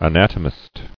[a·nat·o·mist]